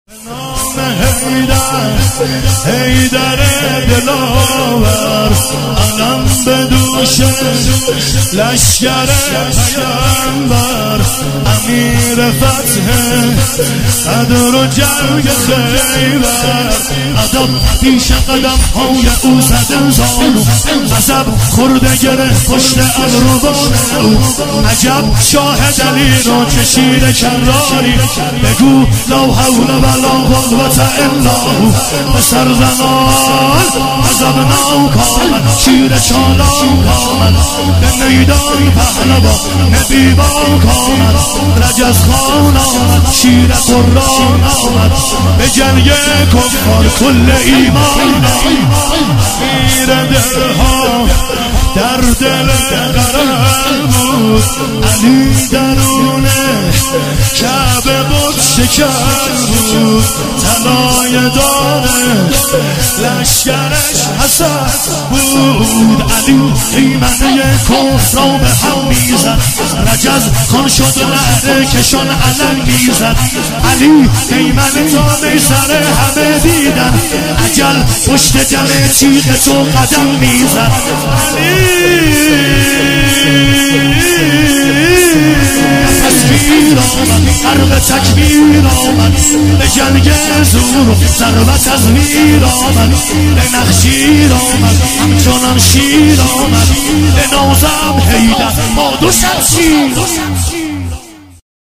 شهادت حضرت معصومه (س) | هیئت جانثاران امام زمان (عج)